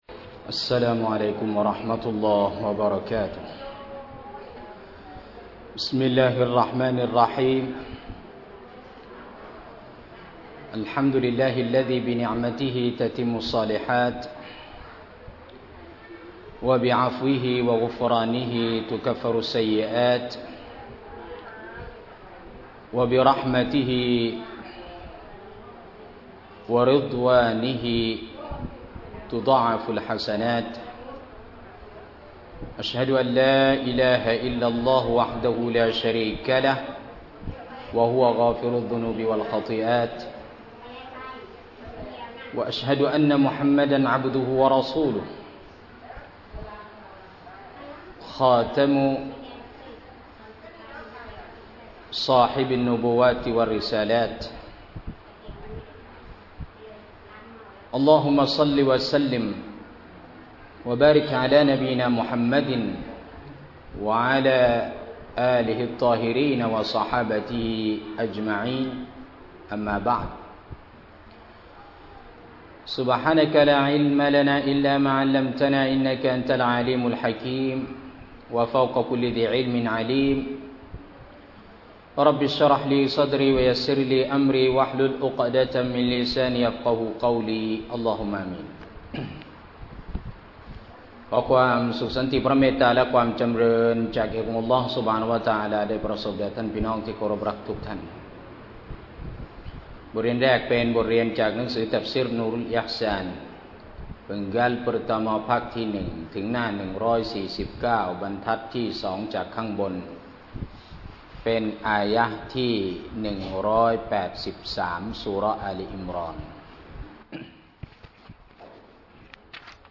สถานที่ :โรงเรียนอิสลามวนาลัย (เราเฎาะฮฺ) ซ.พัฒนาการ 20